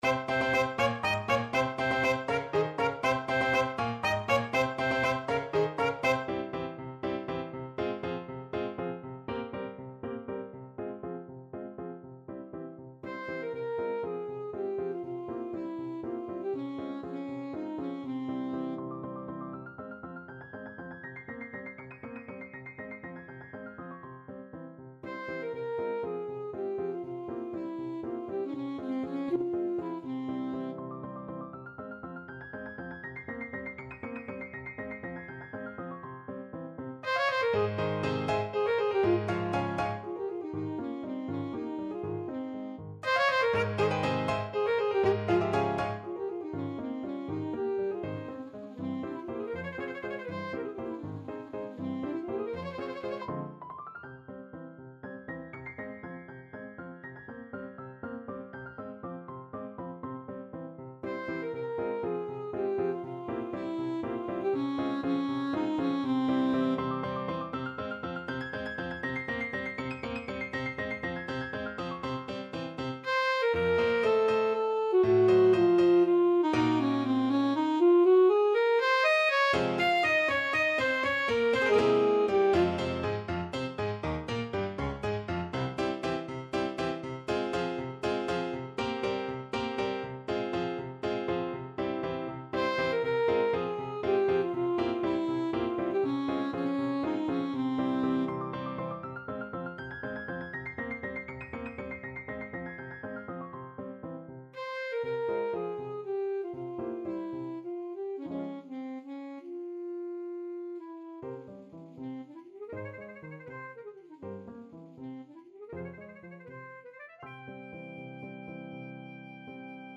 Alto Saxophone
F minor (Sounding Pitch) D minor (Alto Saxophone in Eb) (View more F minor Music for Saxophone )
Allegro vivo (.=80) (View more music marked Allegro)
3/8 (View more 3/8 Music)
Classical (View more Classical Saxophone Music)
bizet_aragonaise_ASAX.mp3